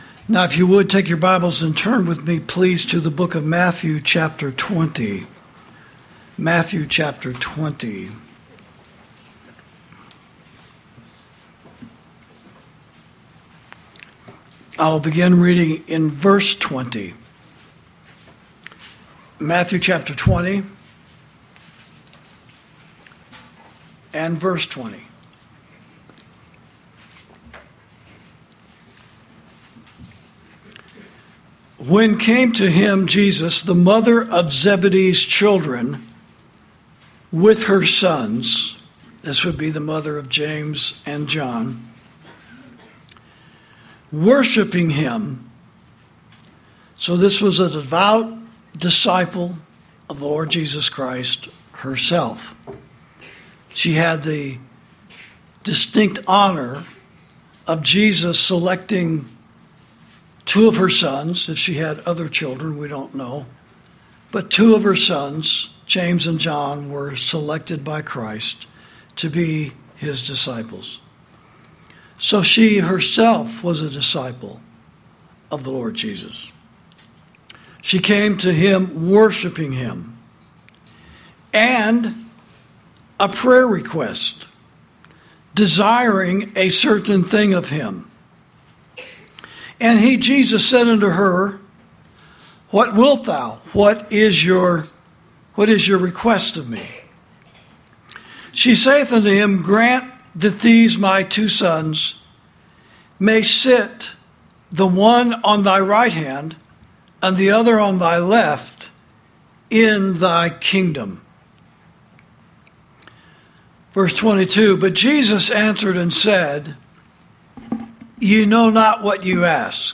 Sermons > Christian Baptism: Water, Spirit And Fire